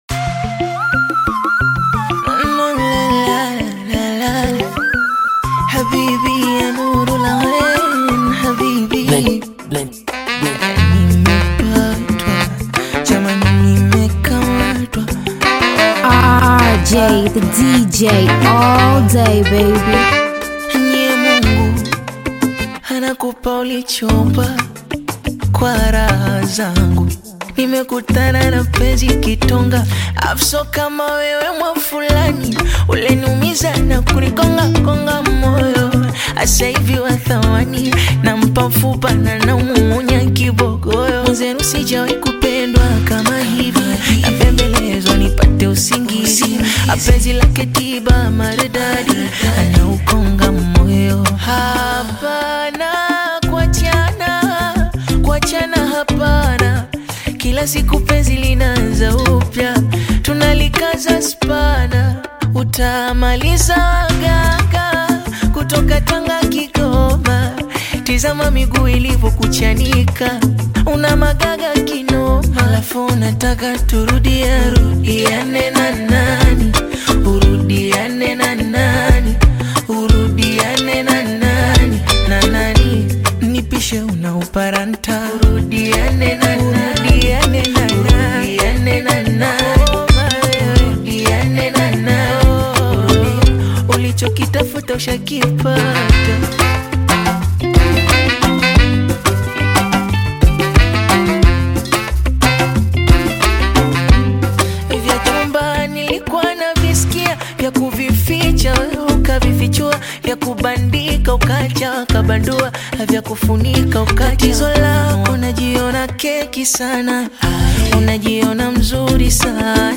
African Music